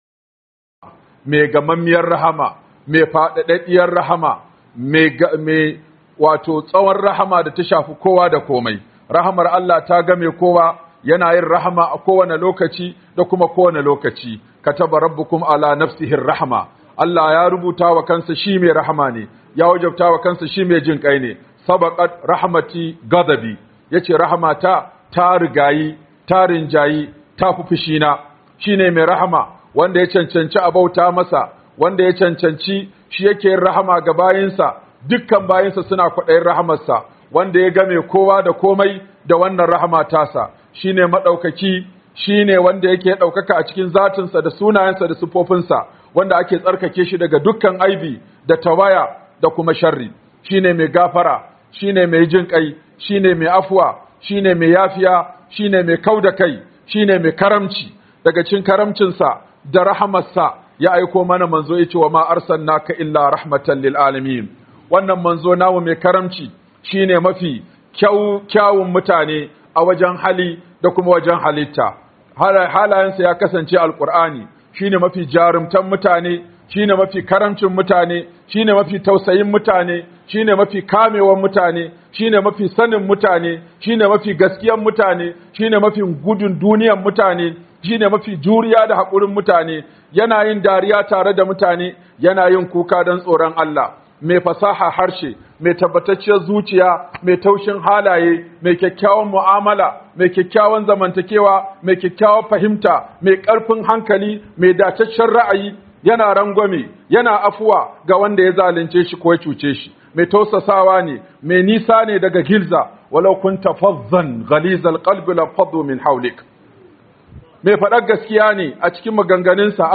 DAGA CIKIN HALAYEN MANZON RAHAMA - Huduba by Sheikh Aminu Ibrahim Daurawa